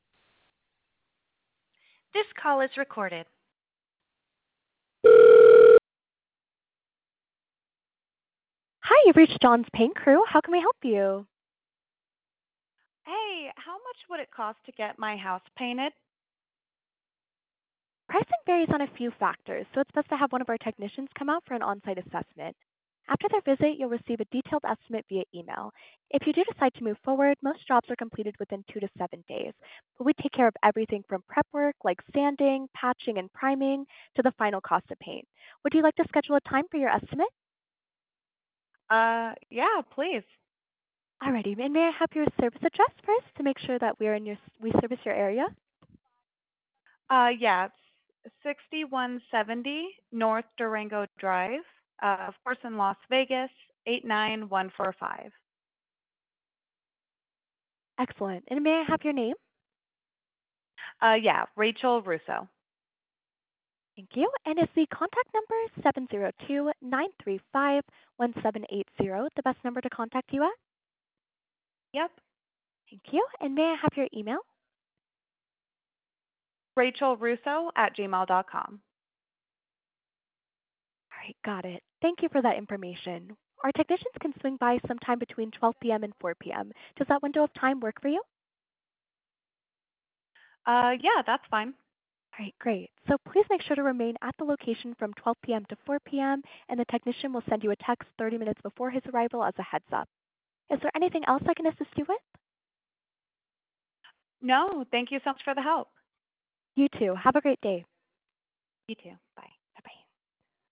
Listen to a demo to hear Abby’s virtual receptionist taking real calls like yours!
HUMAN RECEPTIONIST